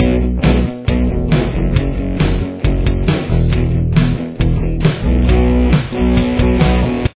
Verse Riff